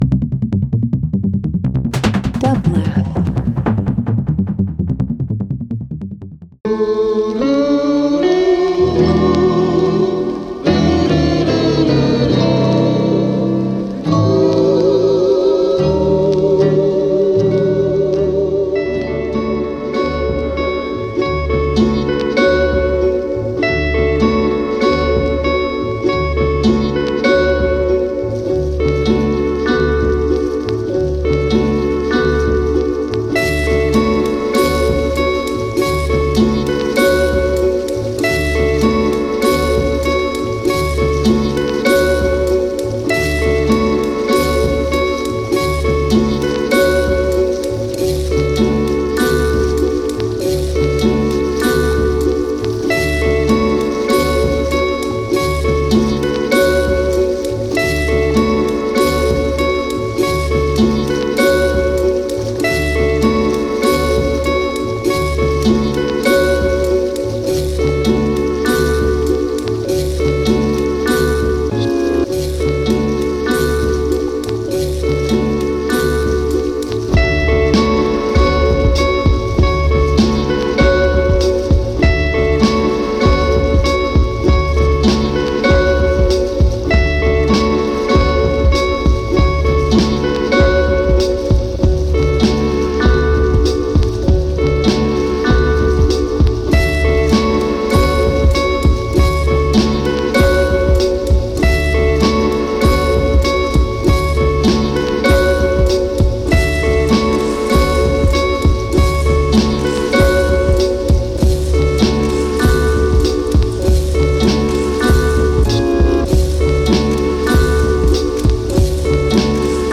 Alternative Electronic Folk